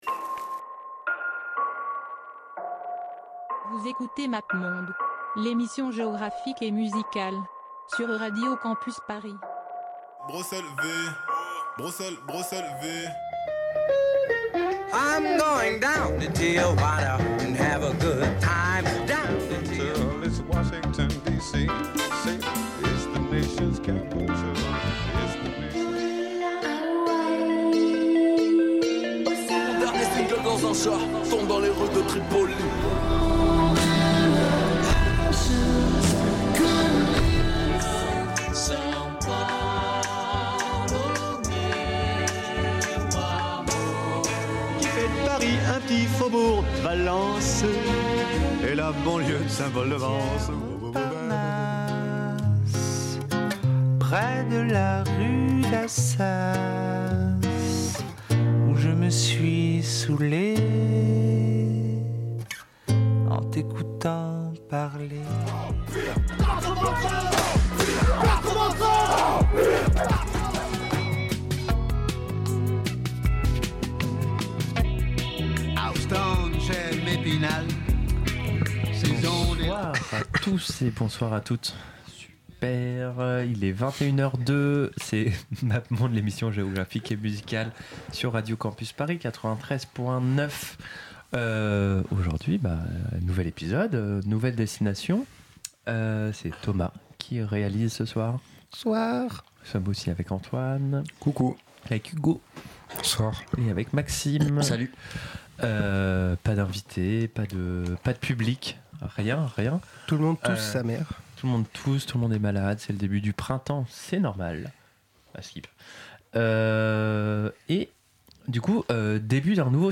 La musique de Micronésie
Musicalement, c'est bien kitsch mais on écoute la musique du peuple Chamorro, des fratries de crooners, des obscurs producteurs philippins et des trucs enregistrés p